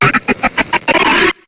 Cri d'Hippopotas dans Pokémon HOME.